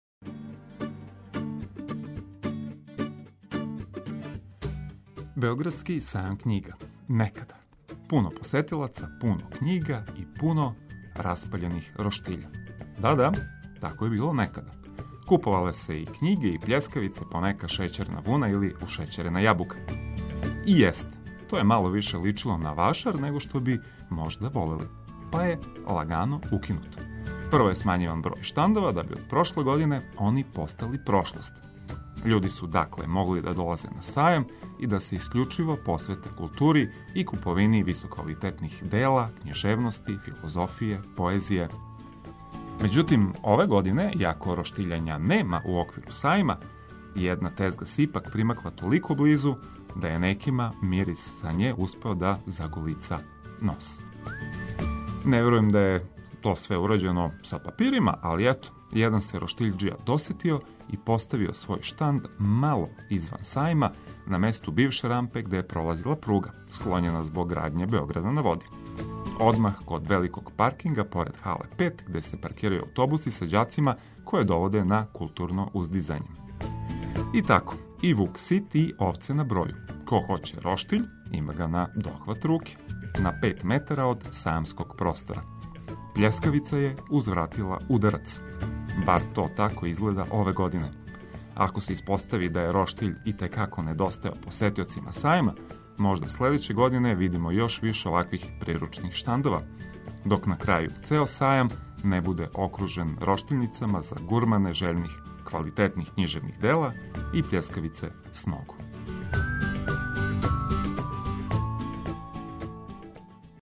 Пробудите се уз добро расположене радио пријатеље.